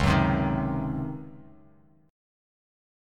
C#M7sus2sus4 Chord
Listen to C#M7sus2sus4 strummed